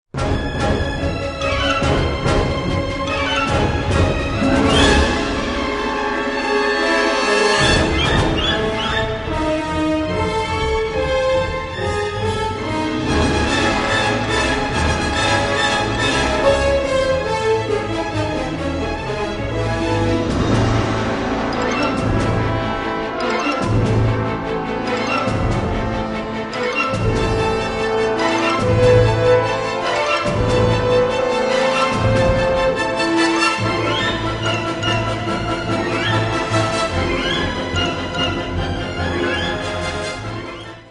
Den bredt malende symfoniske pensel